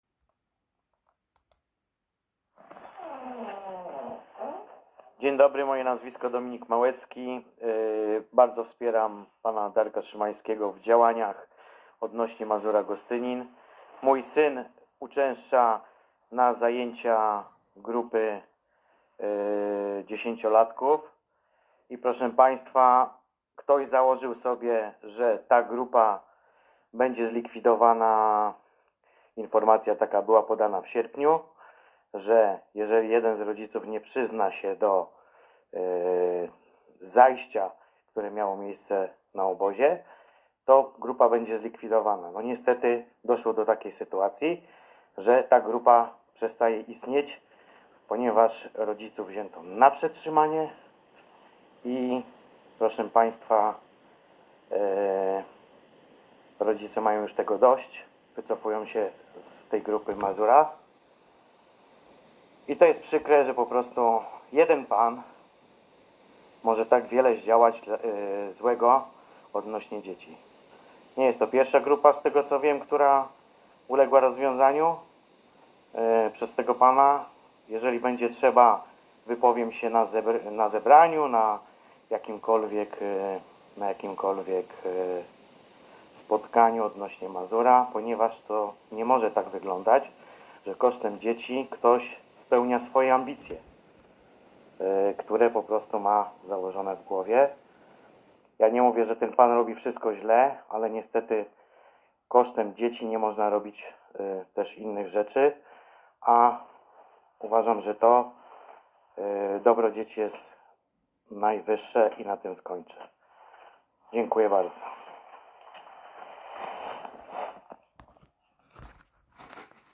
Wypowiedz rodzica :